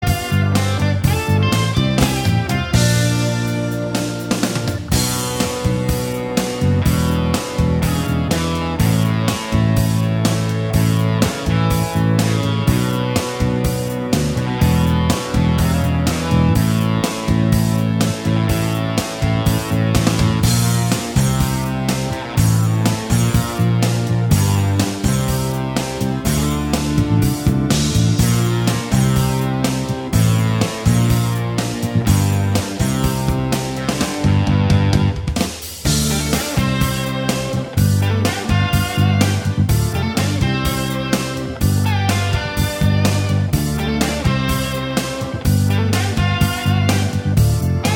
Minus Main Guitar Soft Rock 5:17 Buy £1.50